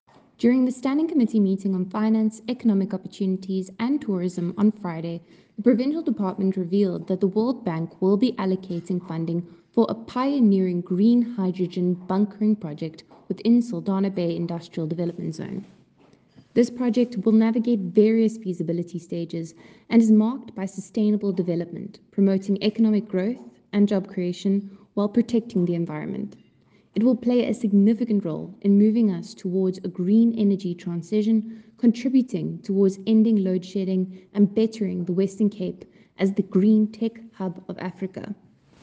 English soundbite from MPP Cayla Murray attached.